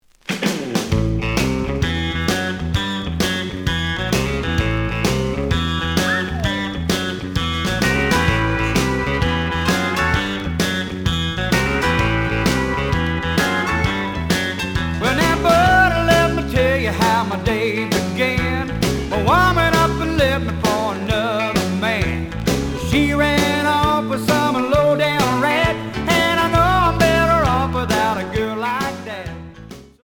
The audio sample is recorded from the actual item.
●Genre: Folk / Country